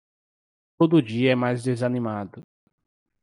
/de.za.niˈma.du/